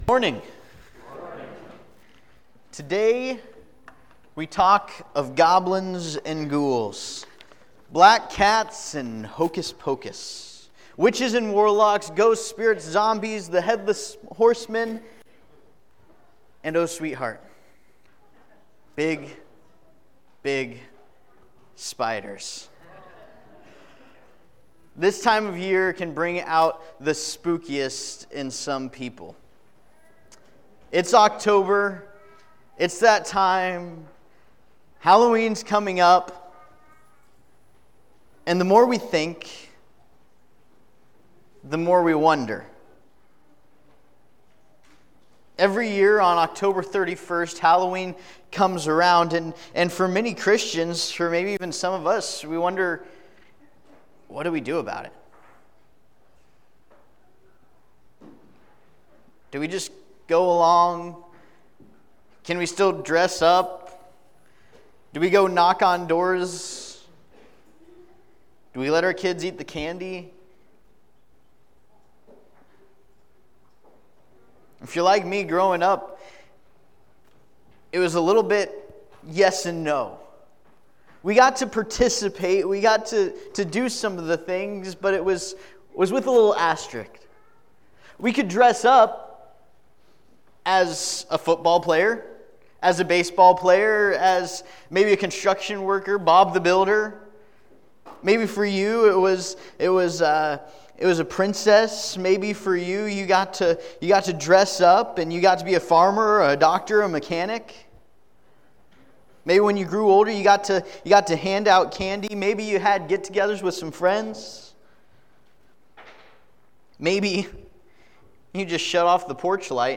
Passage: Romans 8:31-39 Service Type: Sunday Morning